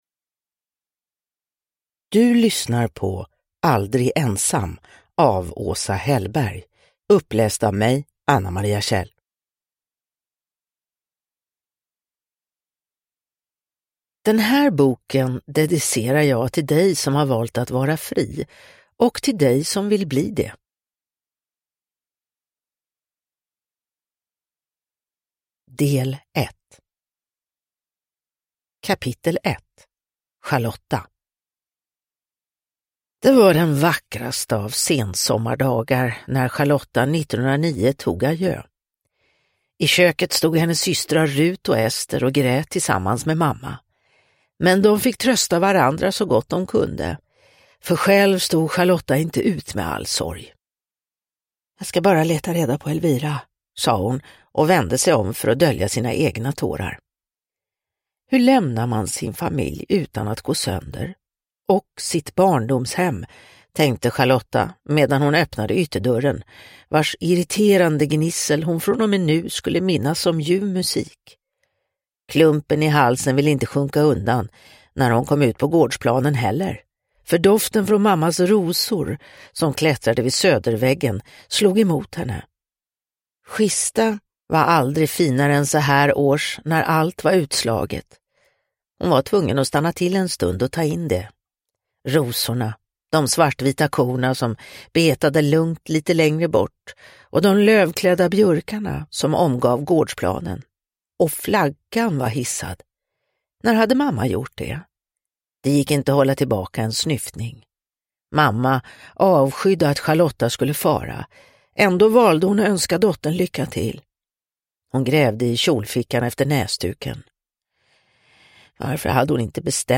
Aldrig ensam – Ljudbok – Laddas ner